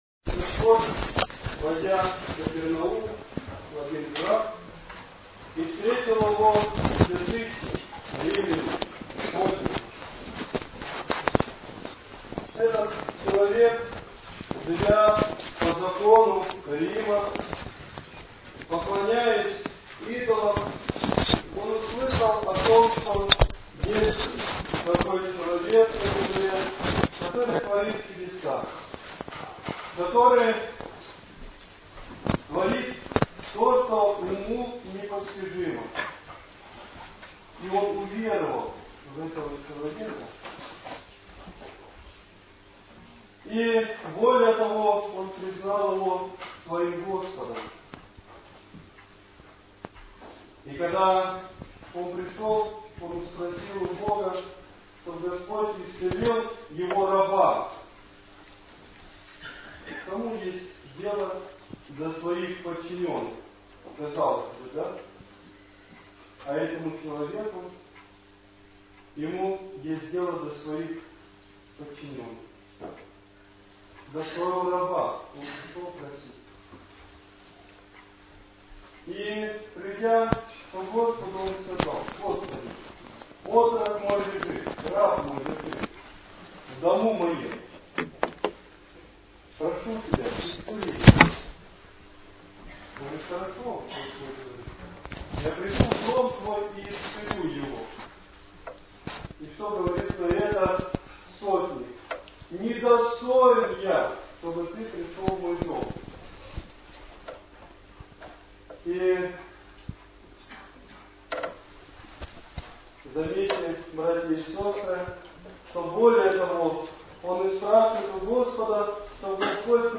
Но запись оказалась низкого качества, поэтому не стал выставлять на сайт.